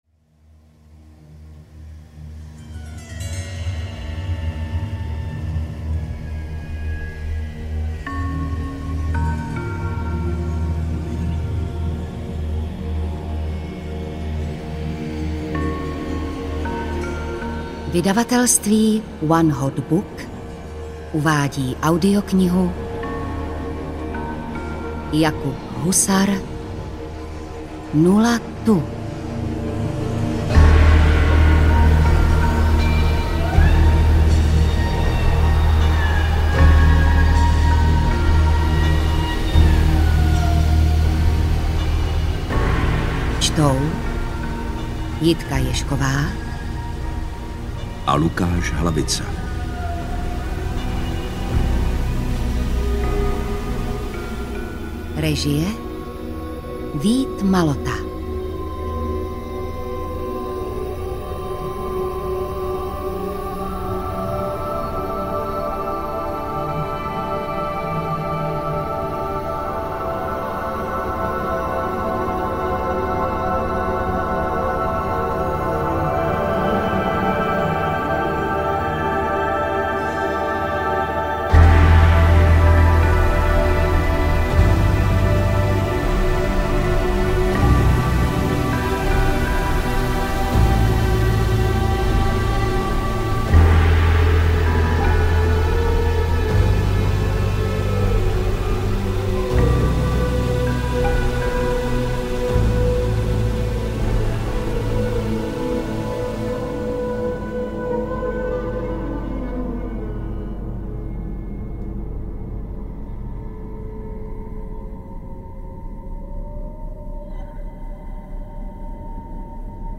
0 TU - svazek I. audiokniha
Ukázka z knihy